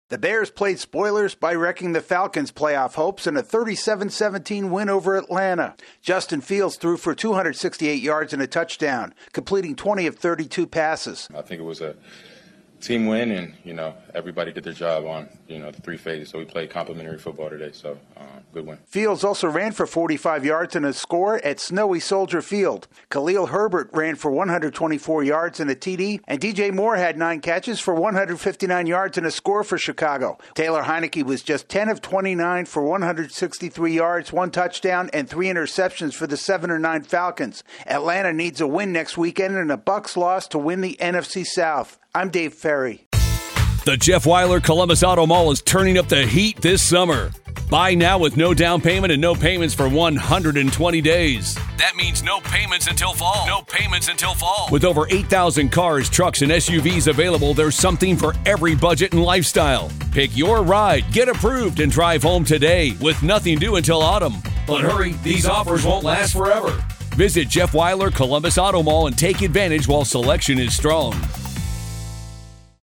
The Bears win for the fourth time in five games. AP correspondent